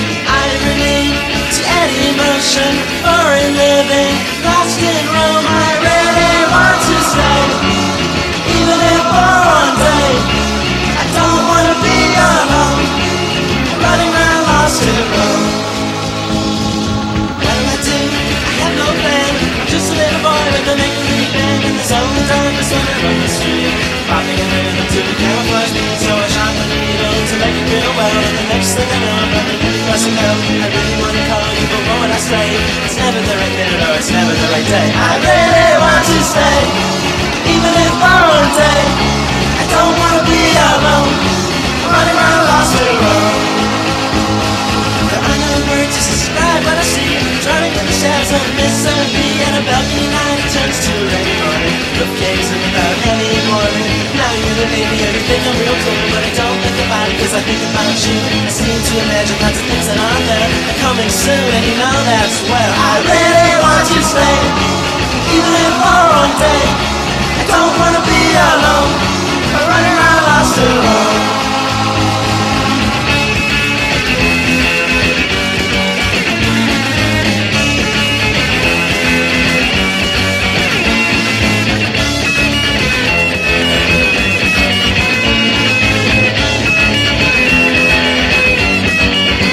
NEO ROCKABILLY / DOO-WOP / OLDIES / ROCK & ROLL
ノスタルジックな胸キュンメロディーにシュビドゥワーなコーラスがたまらないパーティー・チューン